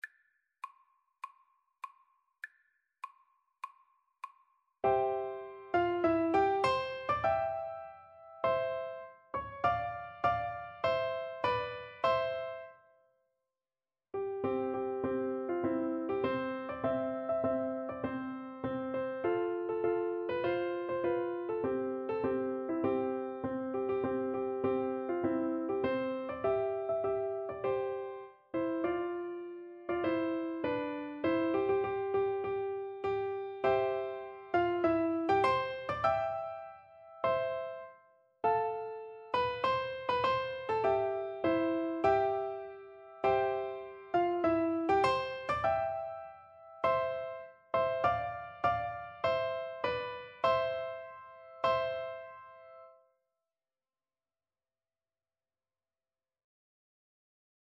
March
4/4 (View more 4/4 Music)
Piano Duet  (View more Intermediate Piano Duet Music)